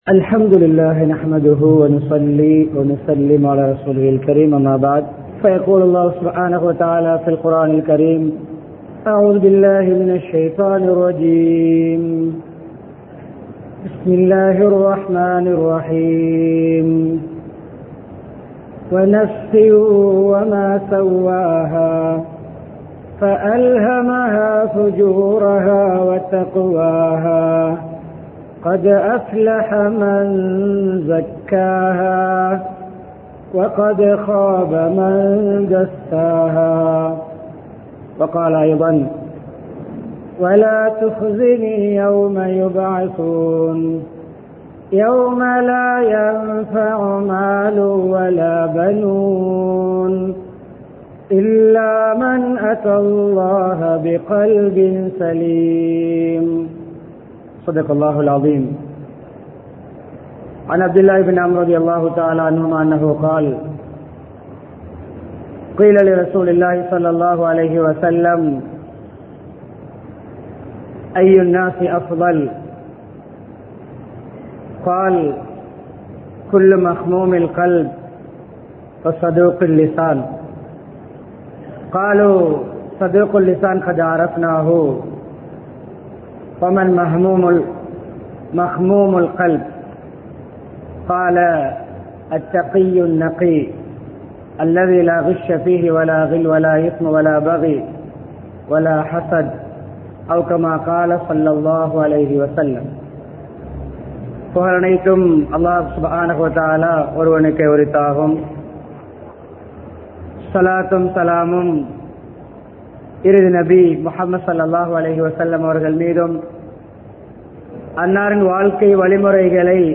தூய்மையான உள்ளம் | Audio Bayans | All Ceylon Muslim Youth Community | Addalaichenai
Town Jumuah Masjith